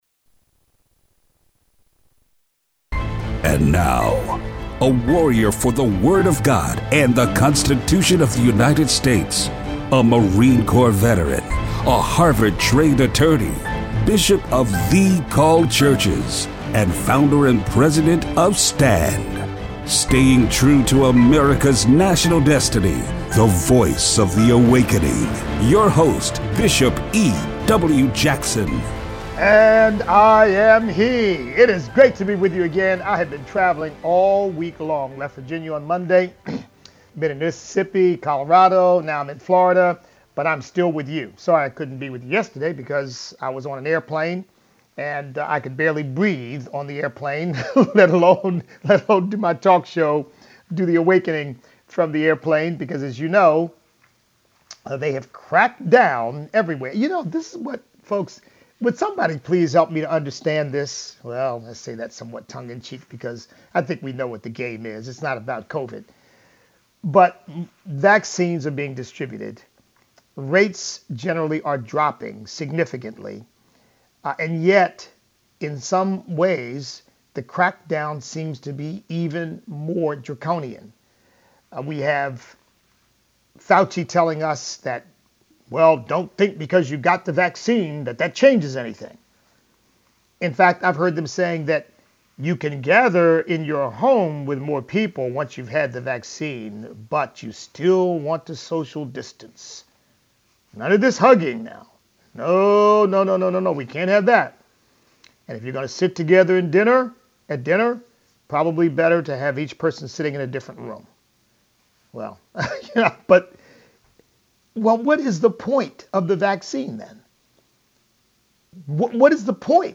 Show Notes Open phone lines!